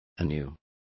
Complete with pronunciation of the translation of anew.